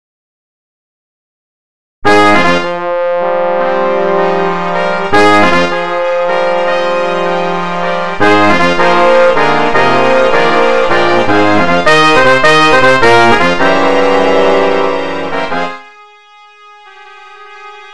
MIDI音源